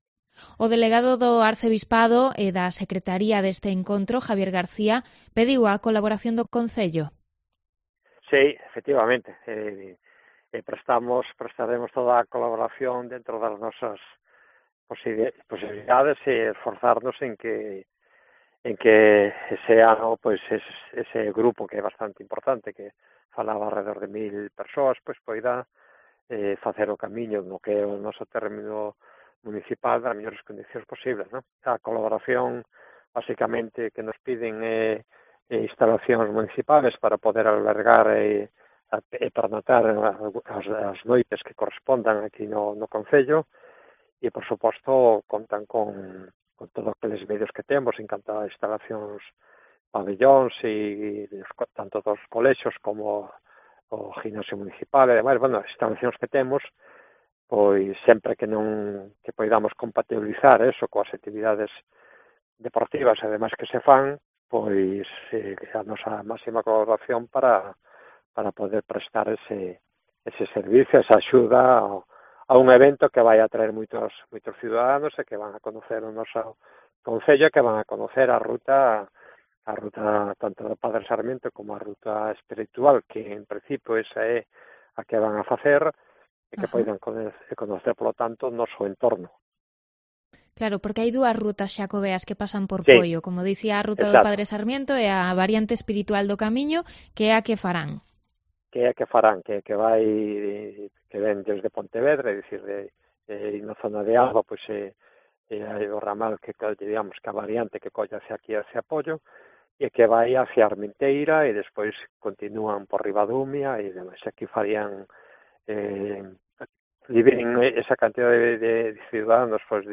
Entrevista al alcalde de Poio por los preparativos para recibir a un grupo de 1.000 peregrinos en el Año Santo